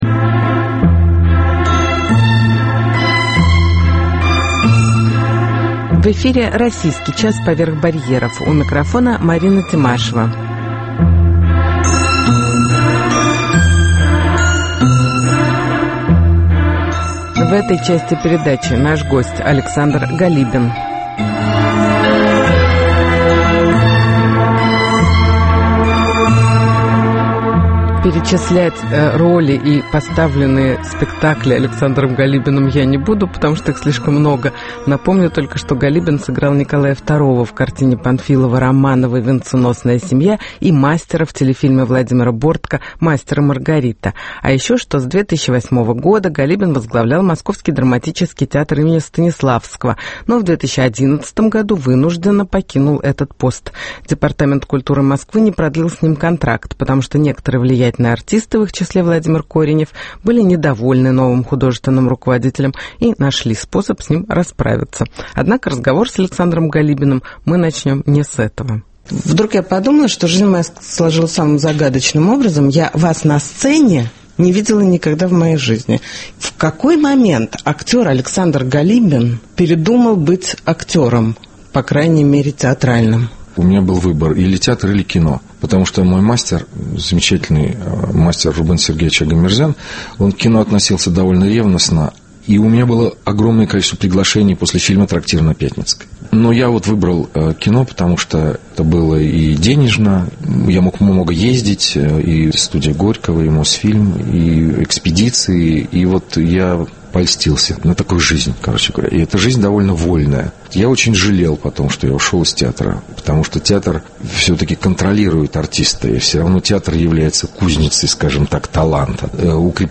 Интервью с Александром Галибиным